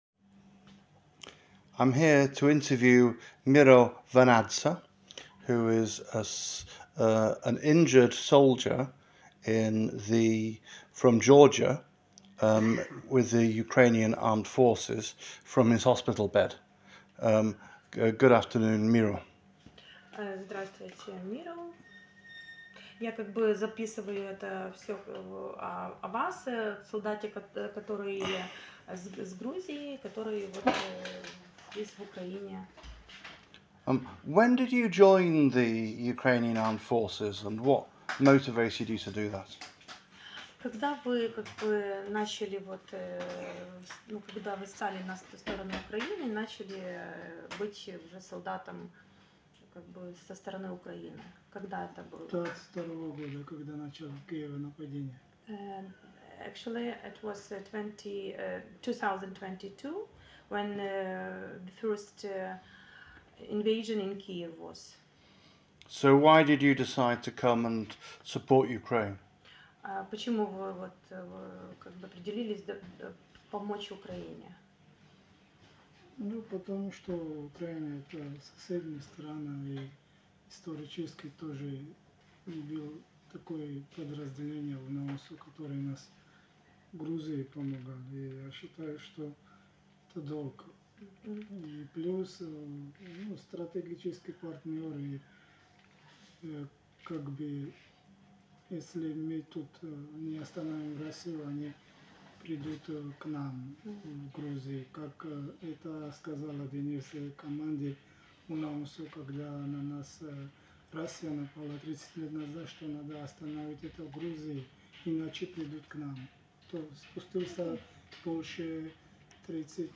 The interview was undertaken with the approval of the hospital and military and other authorities, and indeed in the presence of a number of other military personnel.
We used an interpreter between English and Russian to conduct the interview.